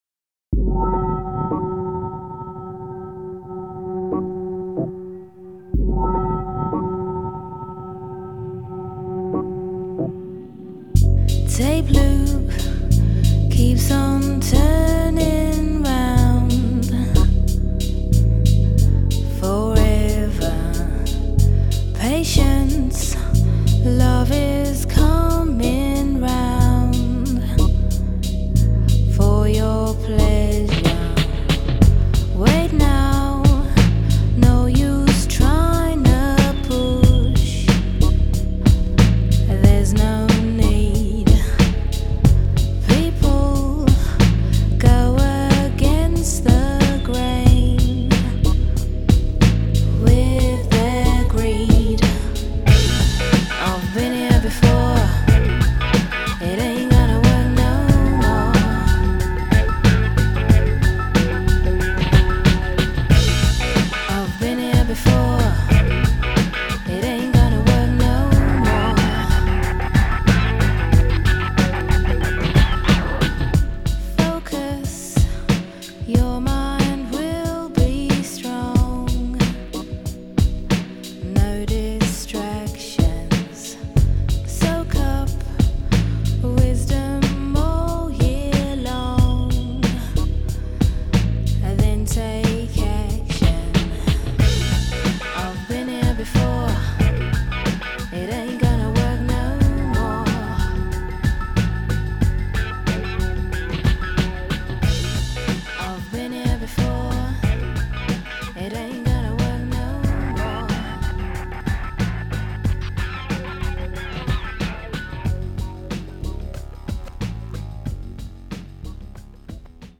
always had a strong streak of soul
wonderful vocals
sometimes quirky but always sharp electronic work